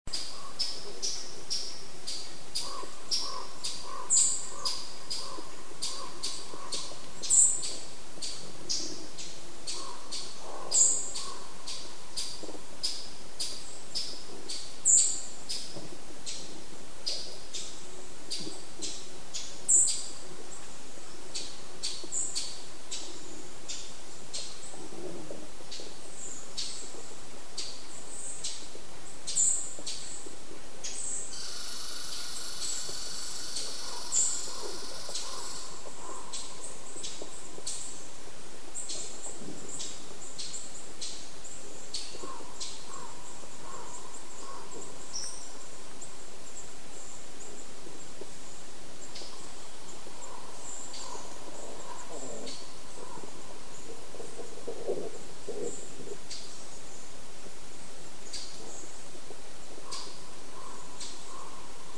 Now many white-throated sparrows are singing, one after the other in response, creating a daisy chain of songs.
Several chatter repeatedly, as if they are protesting my presence.